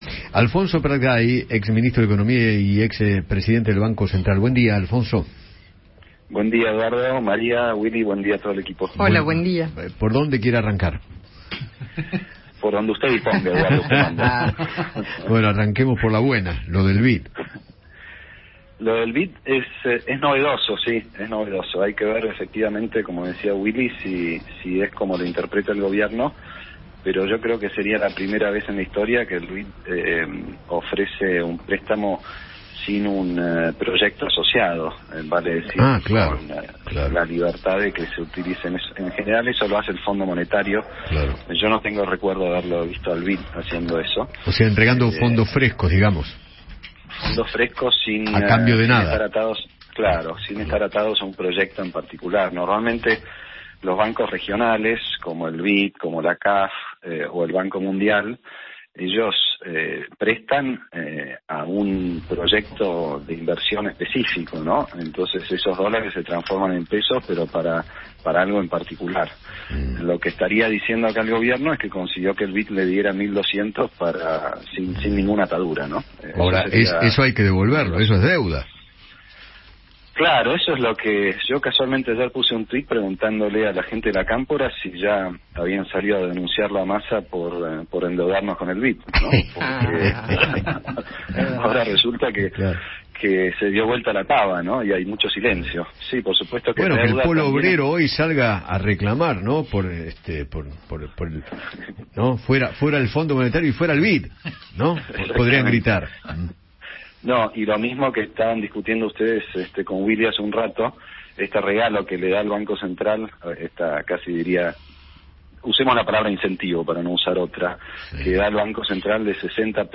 Alfonso Prat Gay, ex ministro de Hacienda de la Nación, conversó con Eduardo Feinmann sobre la gira de Sergio Massa a Estados Unidos y se refirió al acuerdo con el Banco Interamericano de Desarrollo (BID).